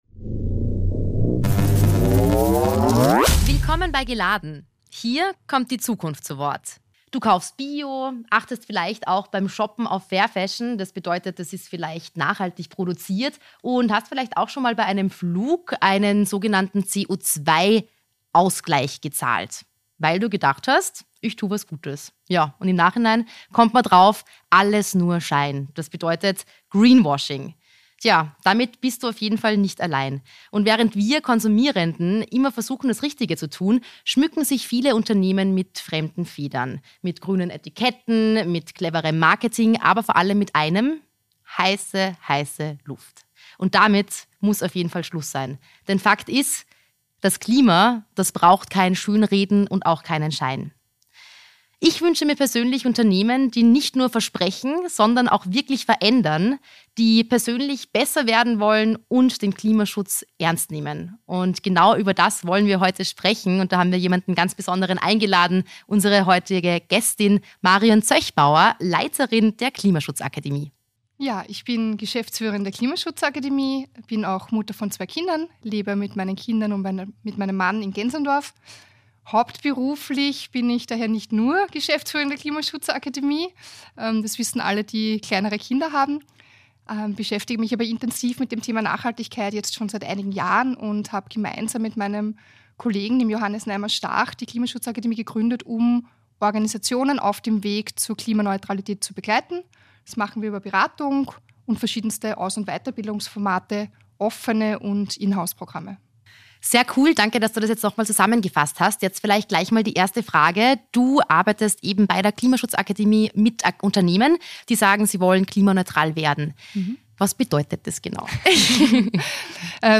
Jeden Monat spricht er in der Arena Wien mit jeweils einer/einem Gesprächspartner:in über neue Ideen und Lösungsansätze rund um Klimaschutz und Nachhaltigkeit.